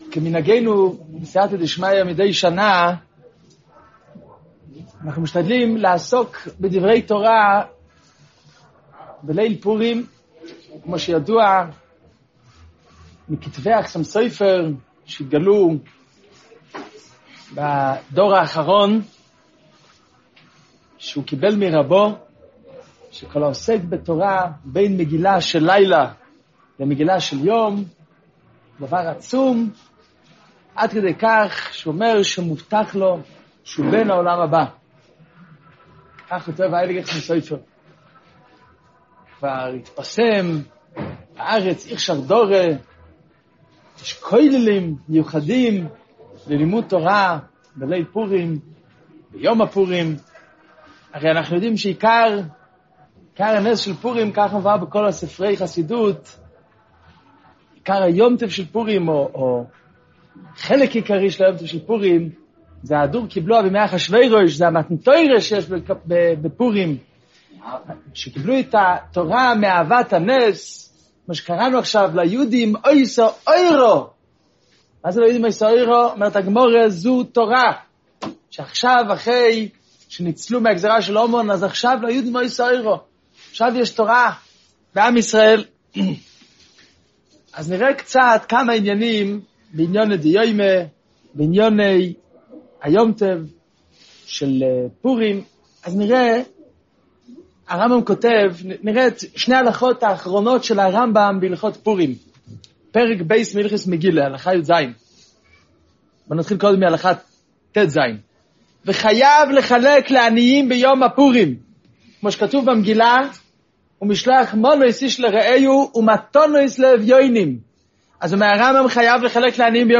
שיעור ליל פורים – תשעט